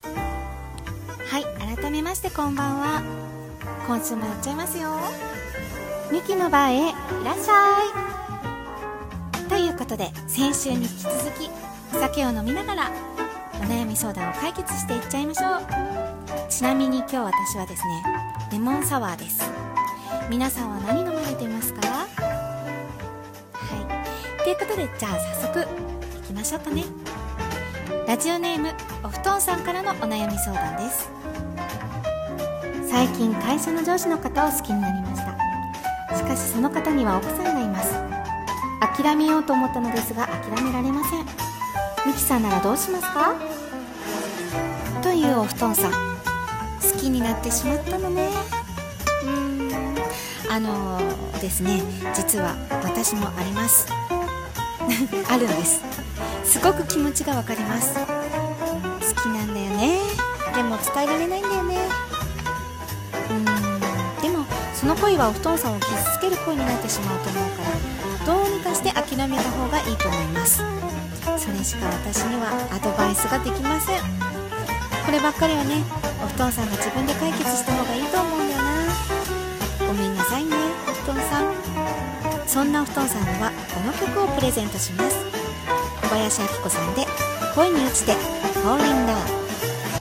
ラジオ風声劇】新コーナー２